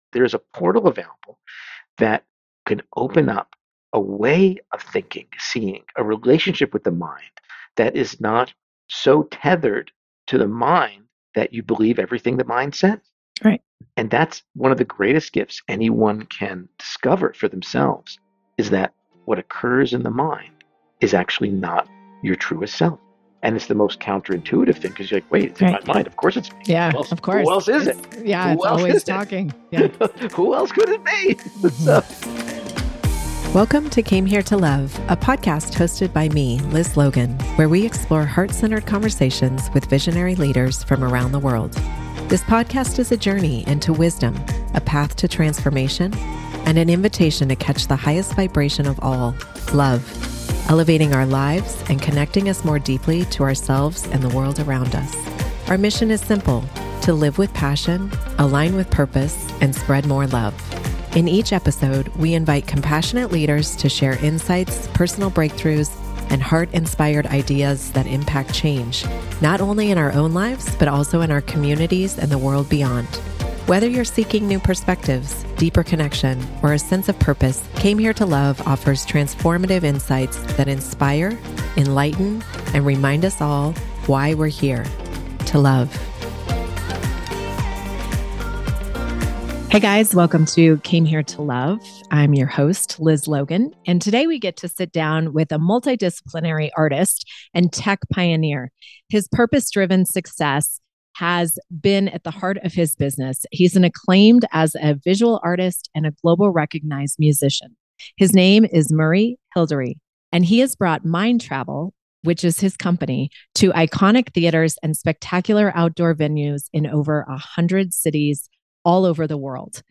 Join us for an unforgettable conversation that will pulse through your heart center, inspire awakening, and foster a greater sense of connection and mindfulness through the power of music an